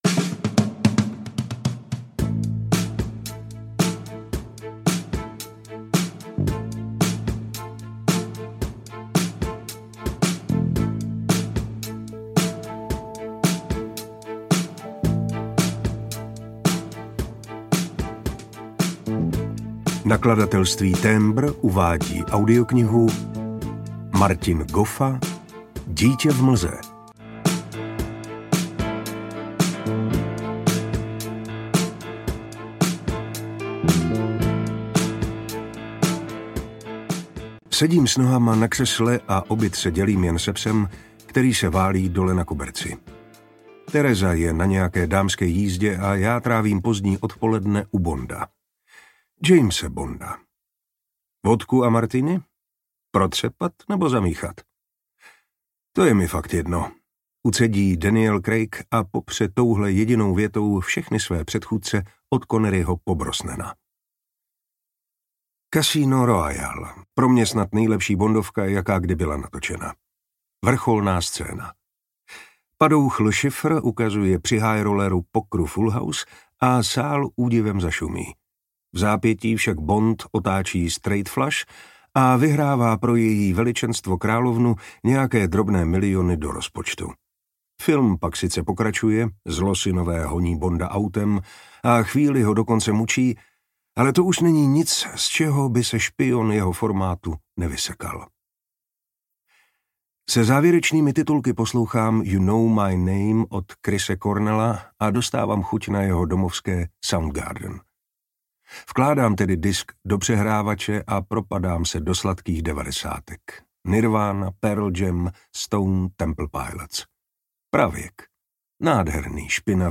Dítě v mlze audiokniha
Ukázka z knihy